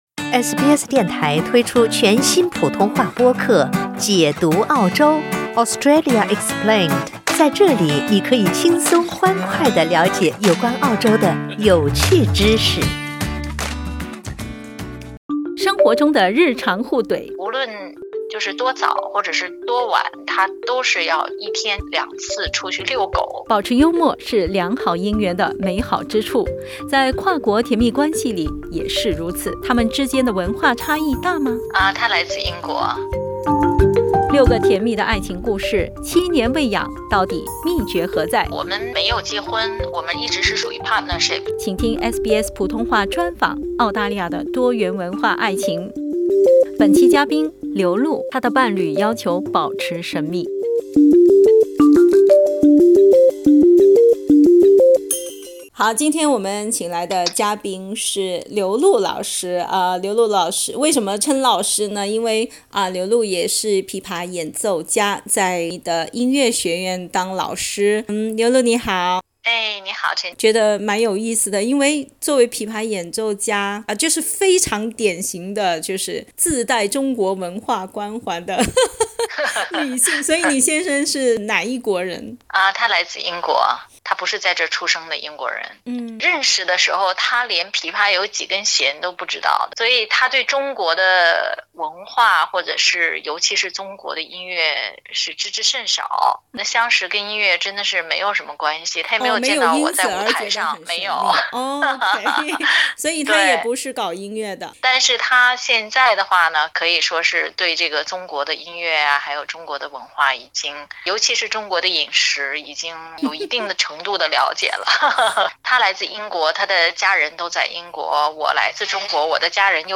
六个甜蜜的爱情故事，七年未痒，到底秘诀何在？SBS普通话专访 《澳大利亚的多元文化爱情》为你解密。